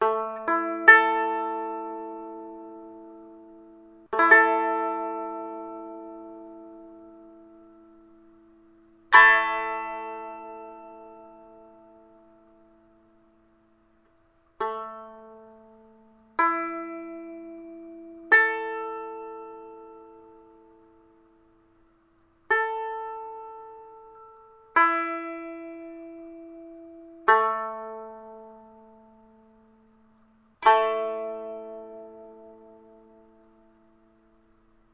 弦乐棒" 弦乐棒1音阶棒材
描述：敲击弹拨用麦克风录制的16bit蓝雪球